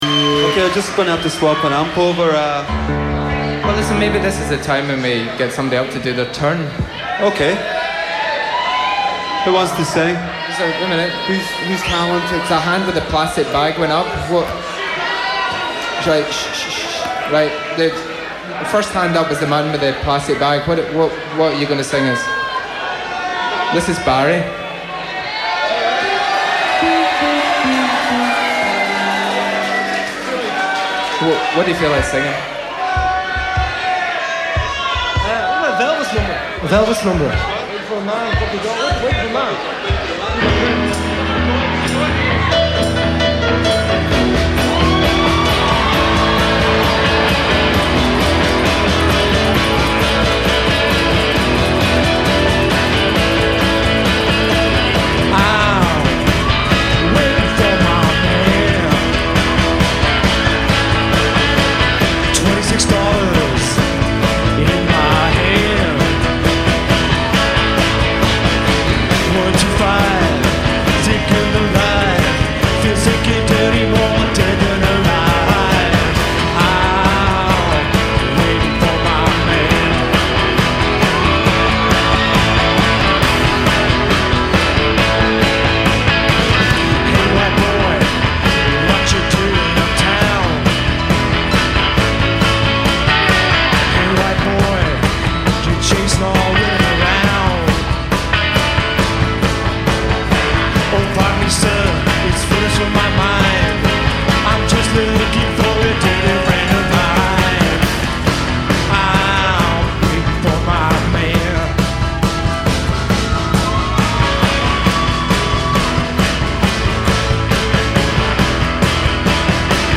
live cover